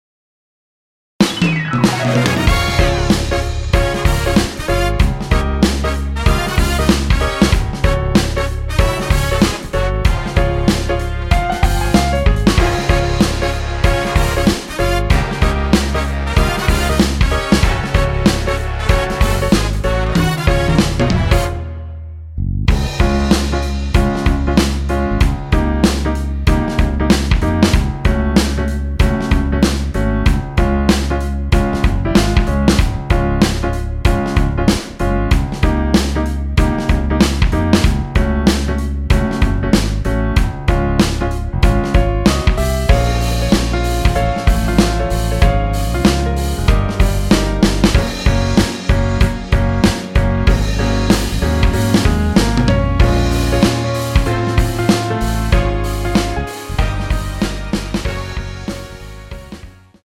원키에서(-1)내린 MR입니다.
Db
앞부분30초, 뒷부분30초씩 편집해서 올려 드리고 있습니다.
축가 MR